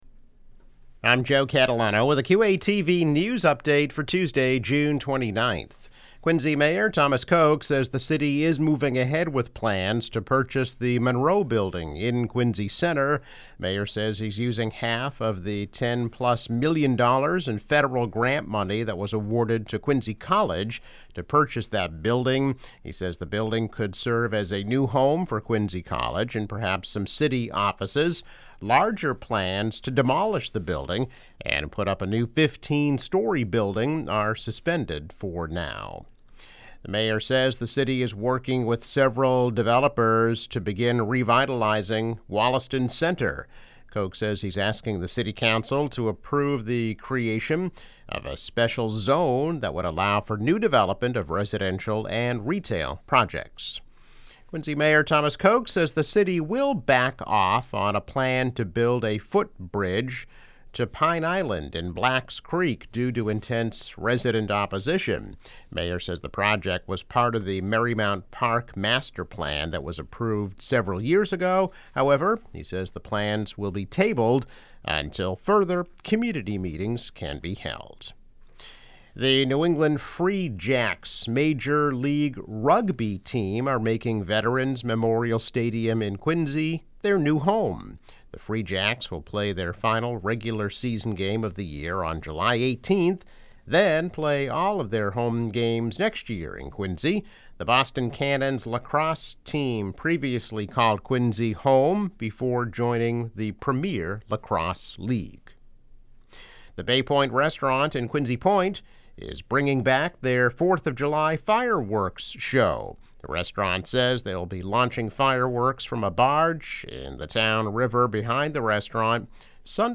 News Update - June 29, 2021